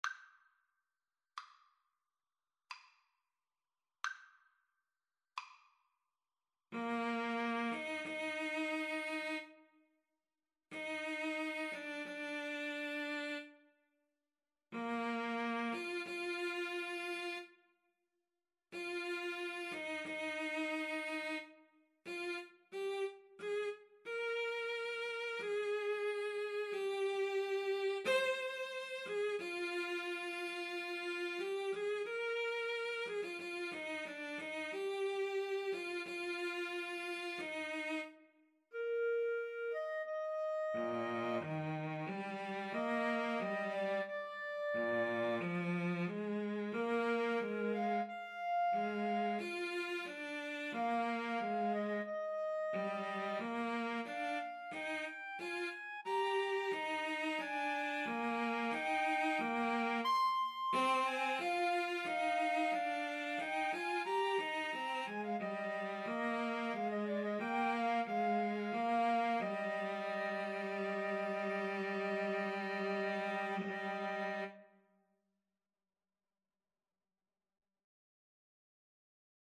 Adagio =45
Classical (View more Classical Mixed Trio Music)